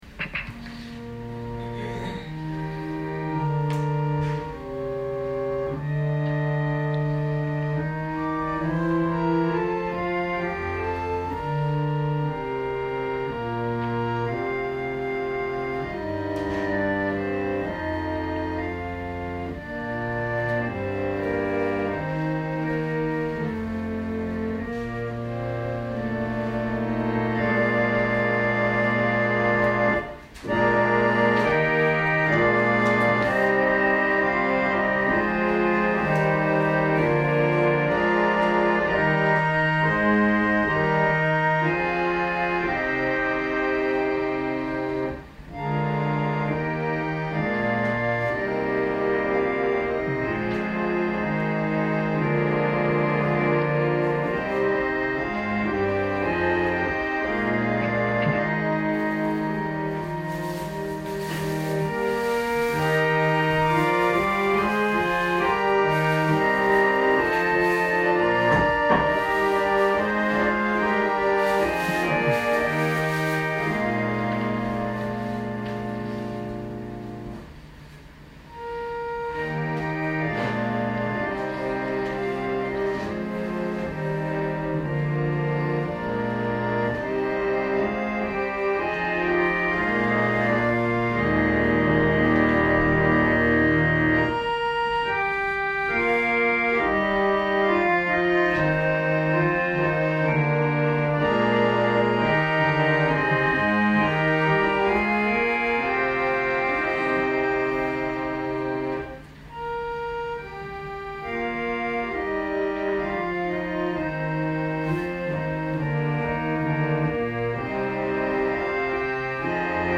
私たちは毎週日曜日10時20分から12時まで神様に祈りと感謝をささげる礼拝を開いています。
音声ファイル 礼拝説教を録音した音声ファイルを公開しています。